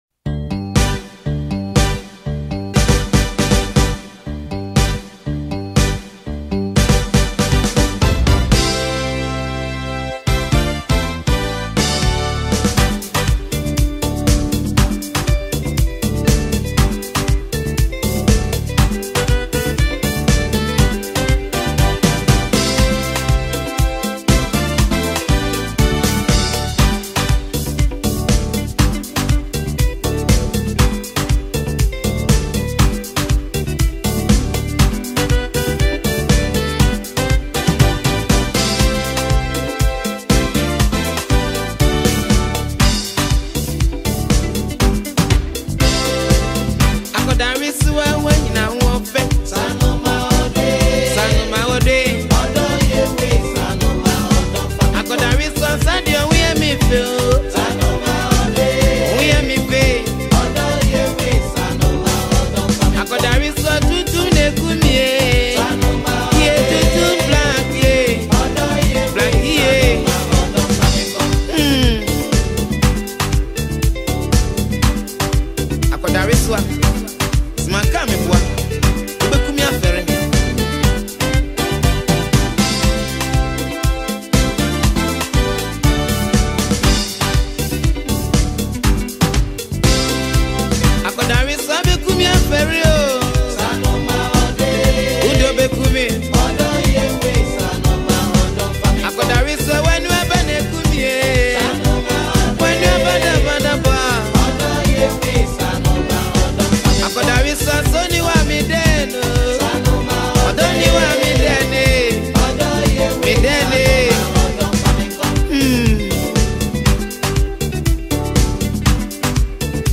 a talented highlife musician
Genre: Highlife